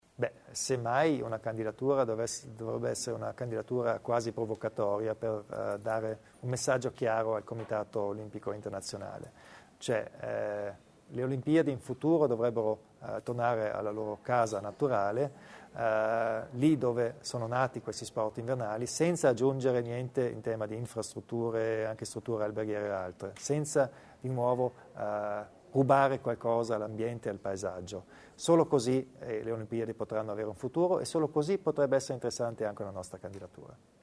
Il Presidente Kompatscher spiega il progetto delle Olimpiadi delle Dolomiti
Nel corso della conferenza stampa di fine anno, Kompatscher ha spiegato di aver già discusso la proposta con il Ministro dello sport Luca Lotti e con il presidente del CONI Giovanni Malagò.